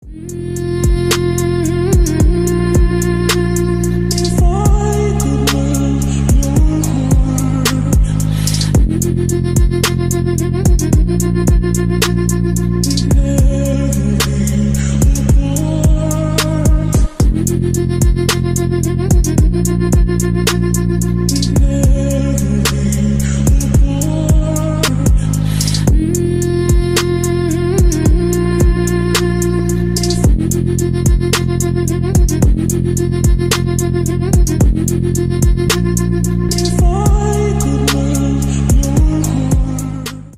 Электроника
спокойные